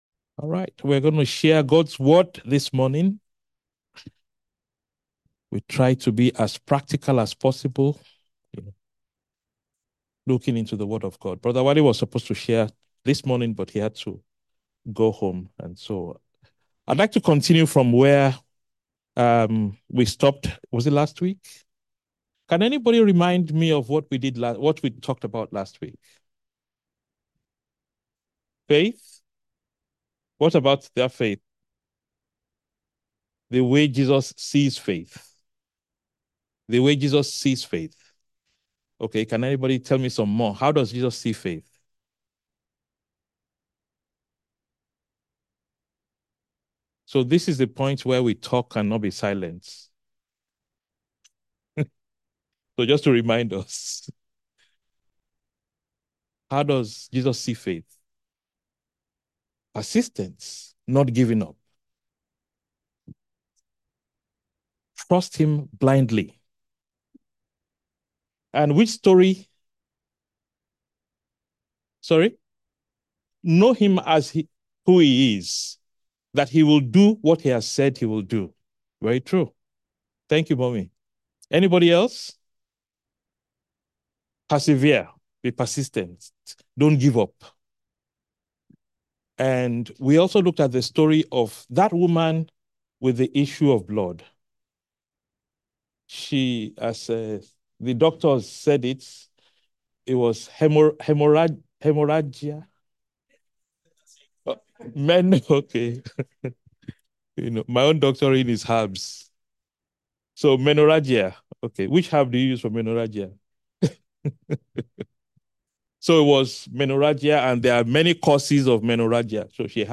A message from the series "Sermons."